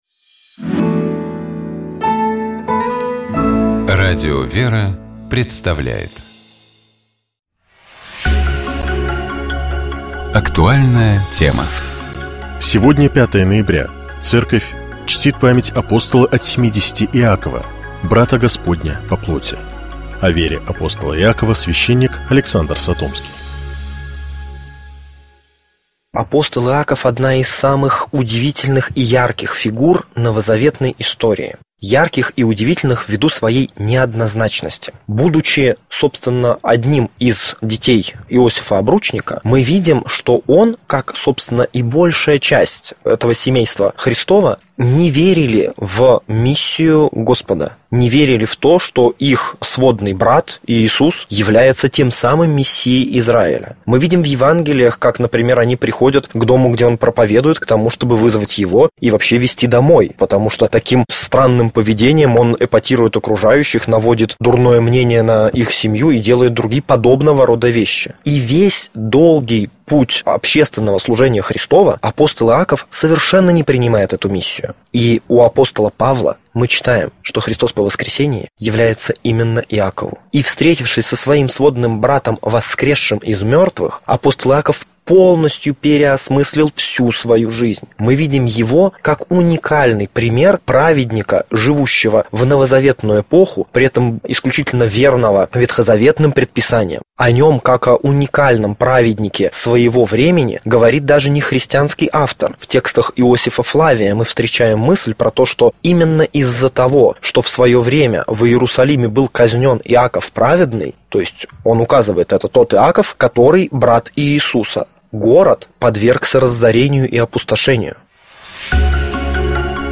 О вере апостола Иакова, — священник